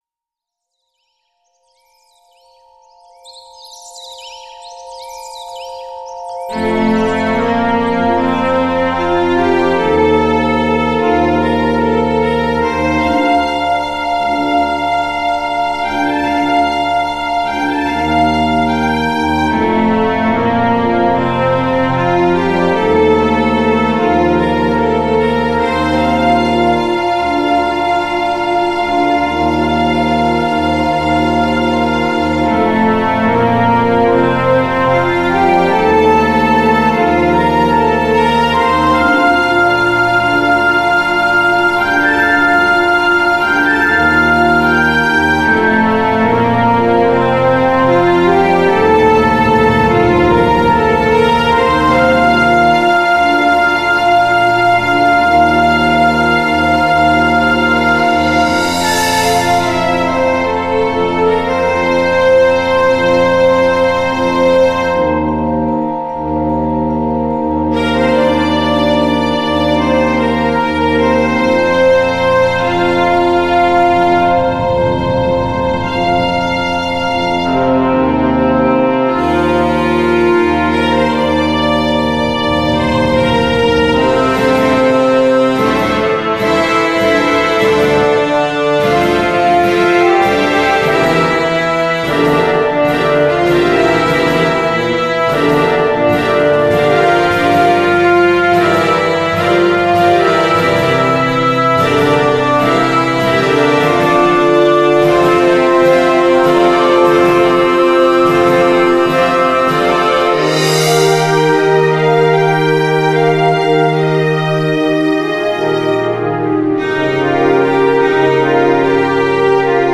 平和が戻り静かな朝を向かえて、これから次の冒険へ旅立とうとするイメージが沸い て出てくる曲ですね。